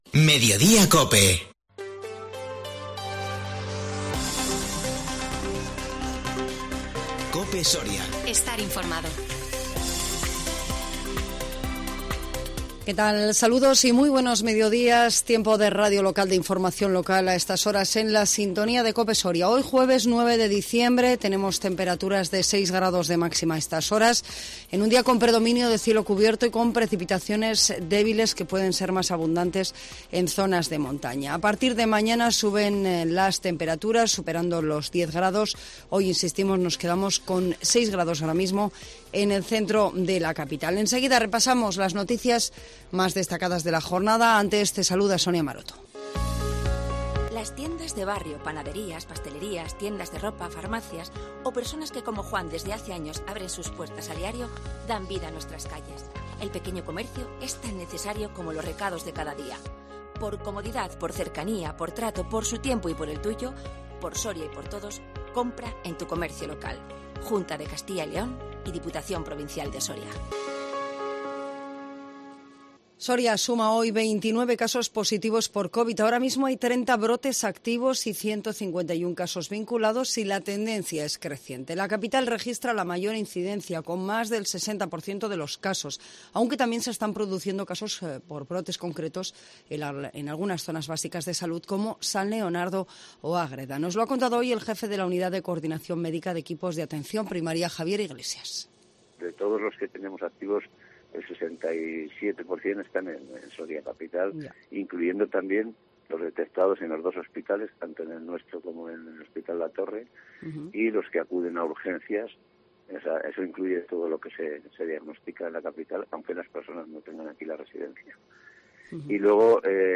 INFORMATIVO MEDIODÍA 9 DICIEMBRE 2021